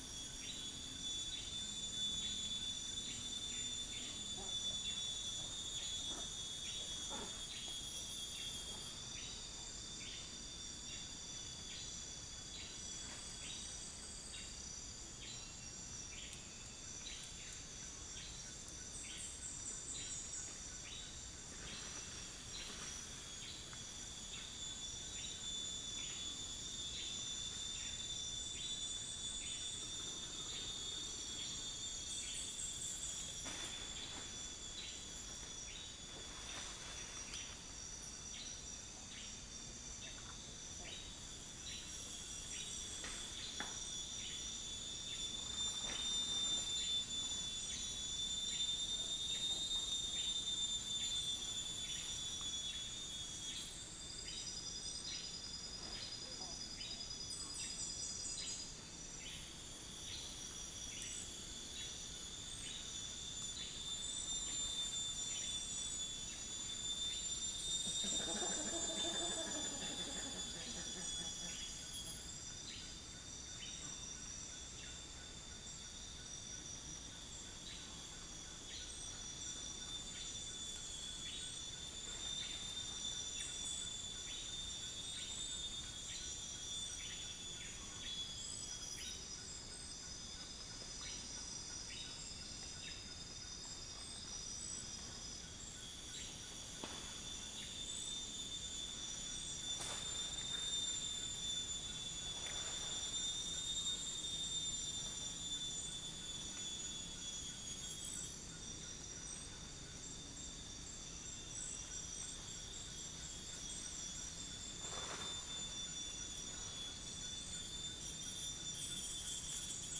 Cyanoderma rufifrons
Corvus enca
Mixornis gularis
unknown bird
Trichastoma malaccense
Dicaeum trigonostigma